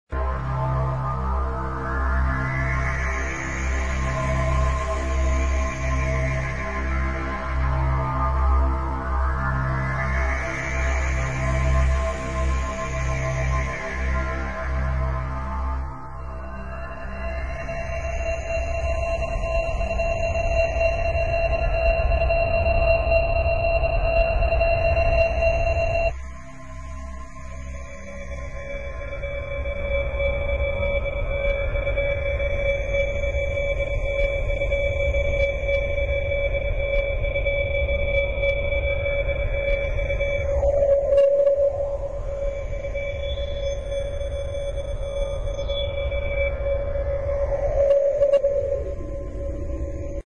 72 Stunden multimediale Kunst 2003